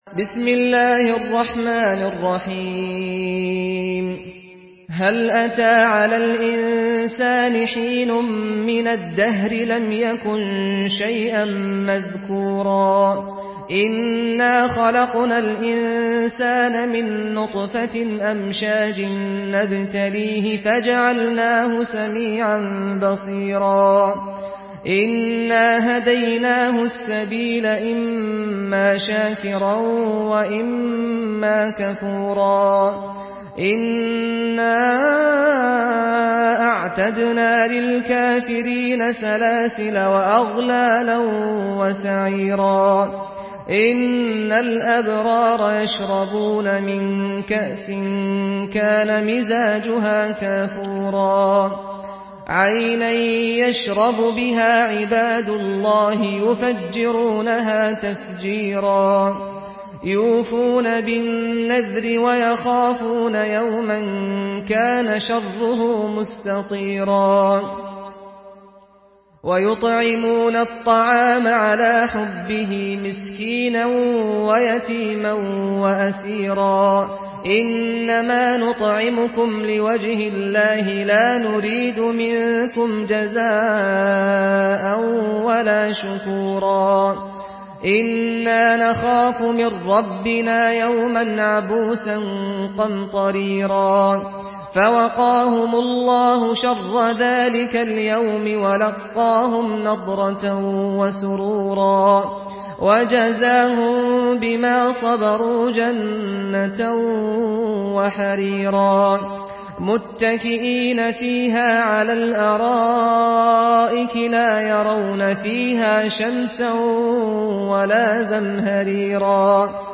Surah Al-Insan MP3 by Saad Al-Ghamdi in Hafs An Asim narration.
Murattal Hafs An Asim